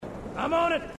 Halo Dialogue Snippets